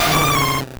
Cri de Cadoizo dans Pokémon Or et Argent.